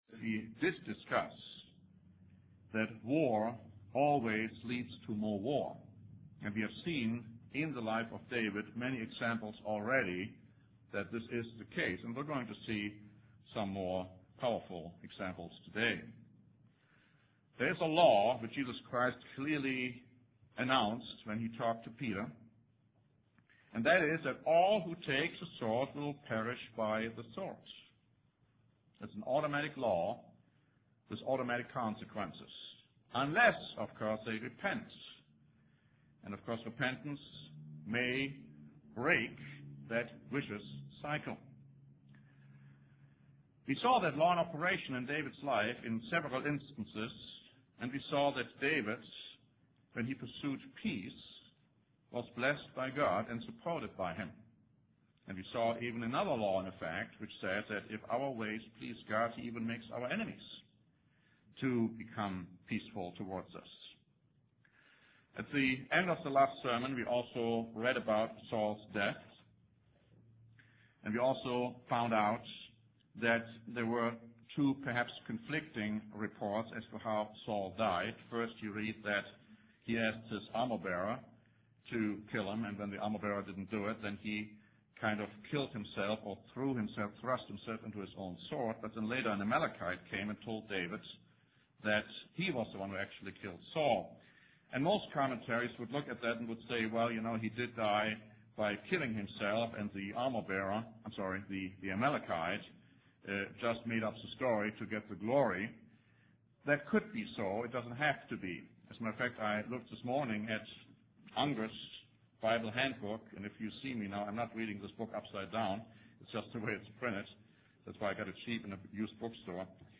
In the last installment of this sermon series, we will discover more undisputable proofs in the life of David, showing that human fighting and killing in war is wrong in God’s eyes.